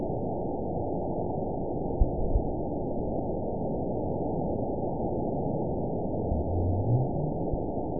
event 920237 date 03/08/24 time 13:20:08 GMT (1 year, 7 months ago) score 9.55 location TSS-AB01 detected by nrw target species NRW annotations +NRW Spectrogram: Frequency (kHz) vs. Time (s) audio not available .wav